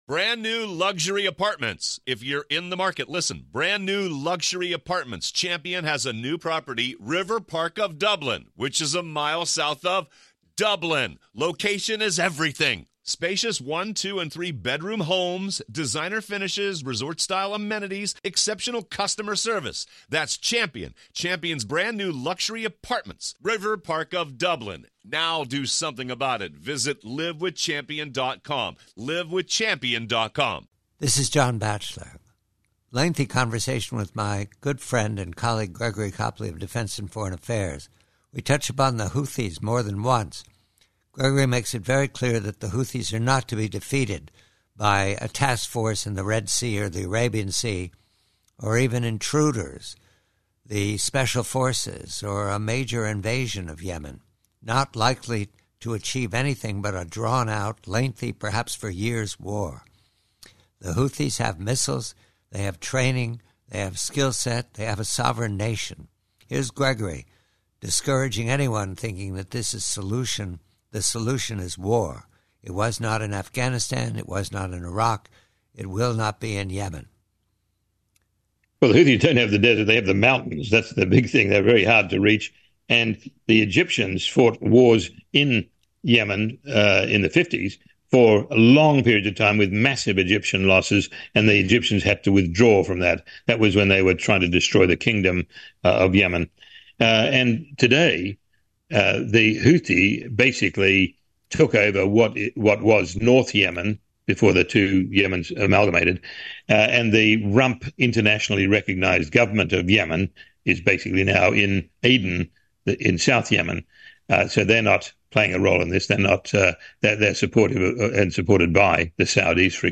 From a longer conversation